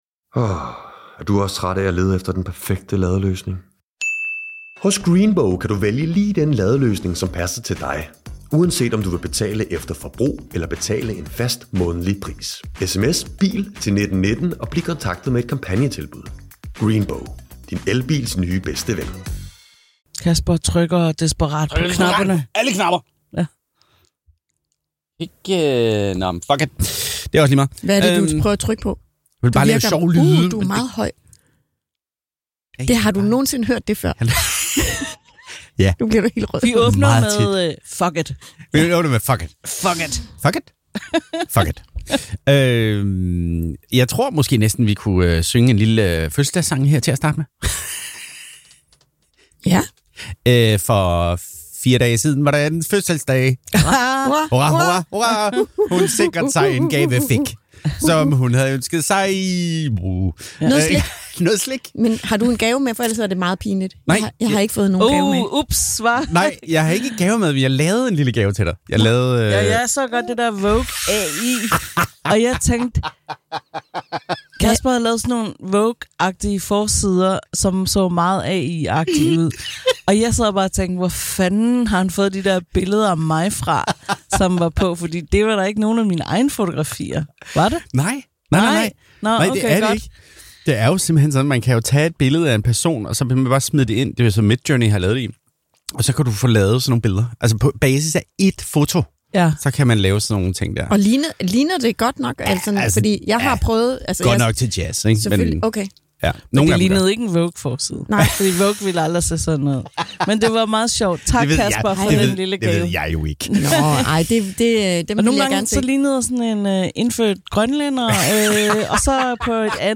Alien: Earth og Peacemaker sæson 2 er begge landet og vi er alle meget glade. Forvent stærk begejstring, dumme grin og et par overraskelser.
Vi har også friskbryggede filmnyheder direkte fra den danske andedam, og så ringer vores første internationale lytter ind med en anbefaling fra det kolde nord.